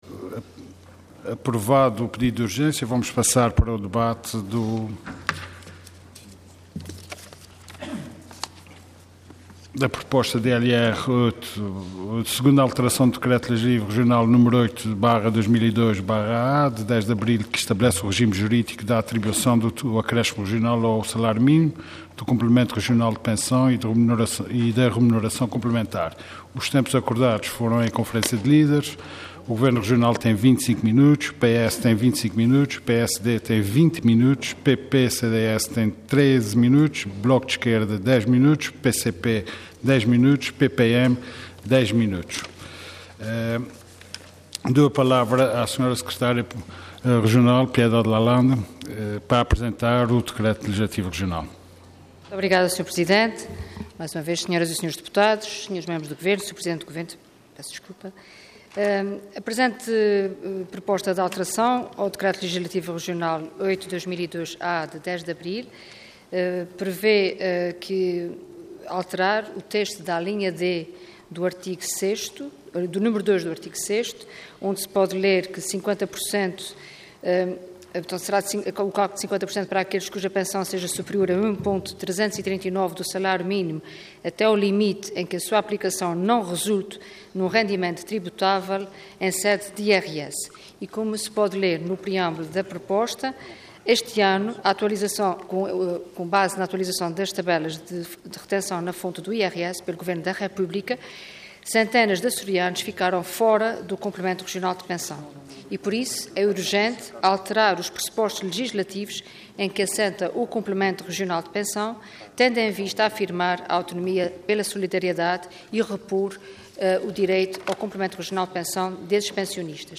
Detalhe de vídeo 17 de abril de 2013 Download áudio Download vídeo Diário da Sessão Processo X Legislatura Segunda alteração ao Decreto Legislativo Regional n.º 8/2002/A, de 10 de abril, que estabelece o regime jurídico da atribuição do acréscimo regional ao salário mínimo, do Complemento Regional de Pensão e da Remuneração Complementar. Intervenção Proposta de Decreto Leg. Orador Piedade Lalanda Cargo Deputada Entidade Governo